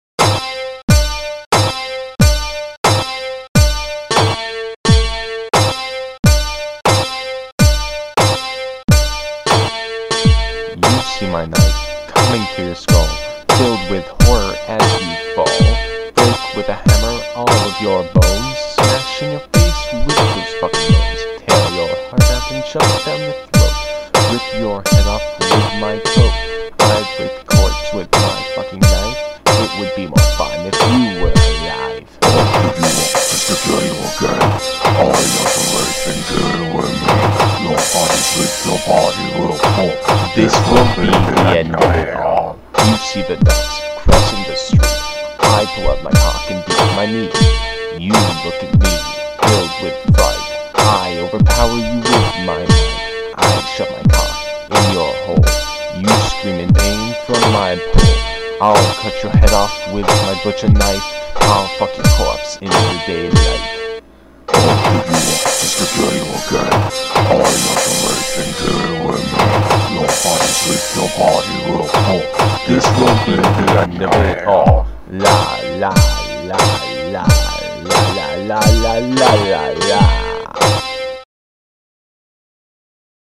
All My Love (Alternate Version)- This is some sort of strange version I decided to make. My original intention was to make a techno version, but it didn't quite turn out that way.